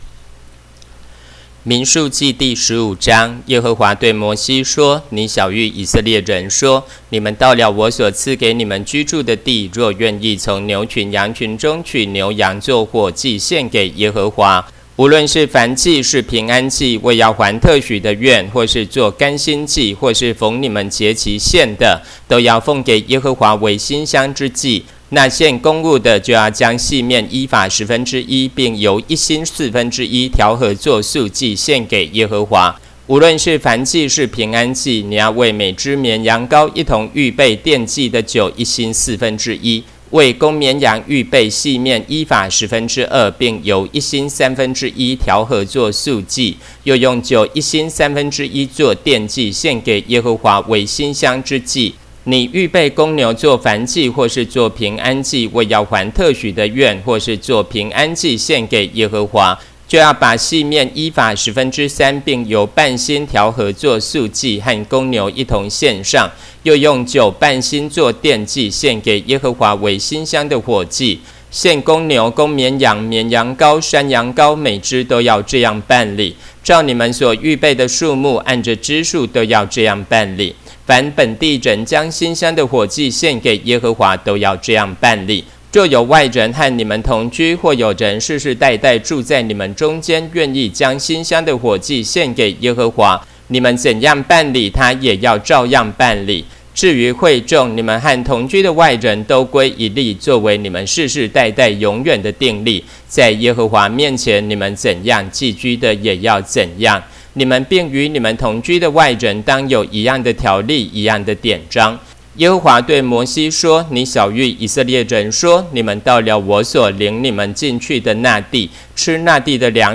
Spring 版和合本有聲聖經